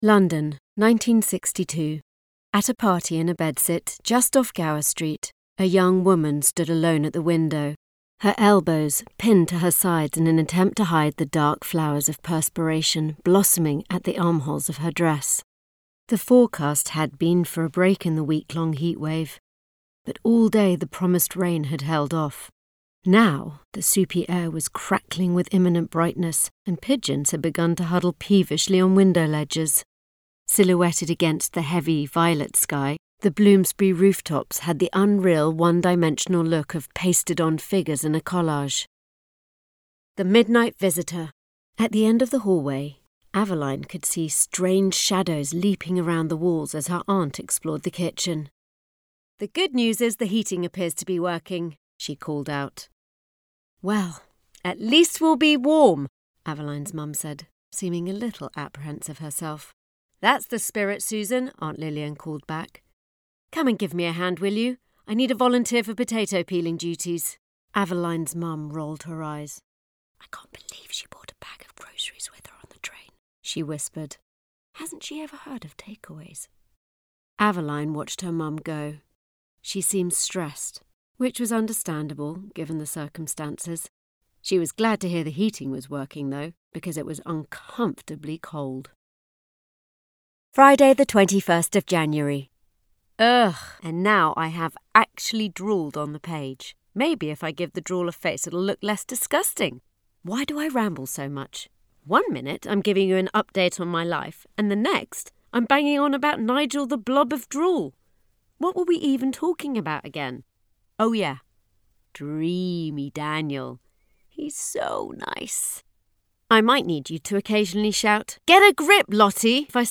Fresh, likeable and articulate.